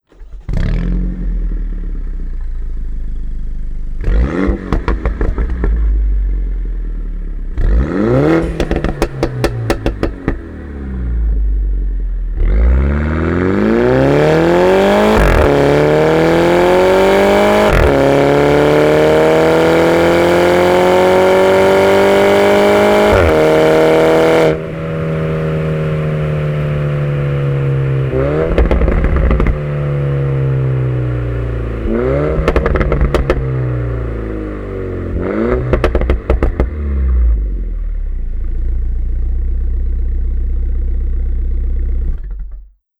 JCWチューニングキット装着時